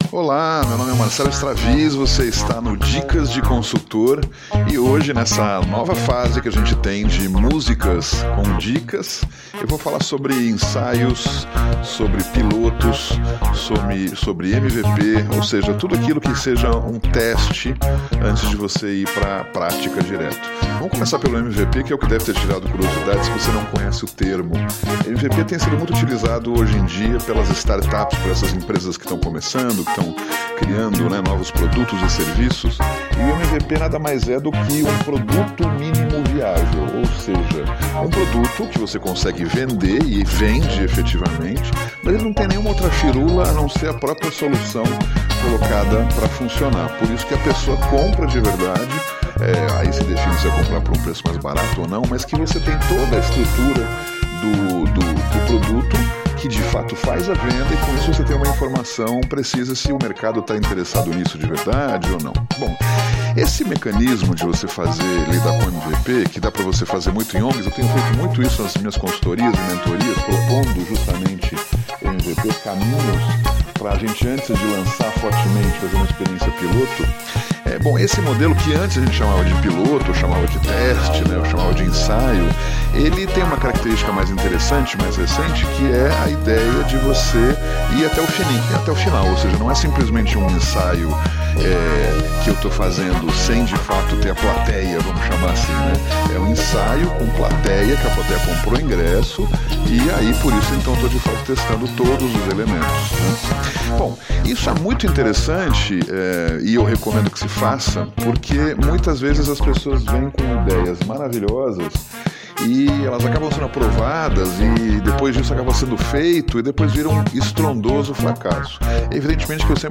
Ou melhor, virou uma excelente instrumental deles!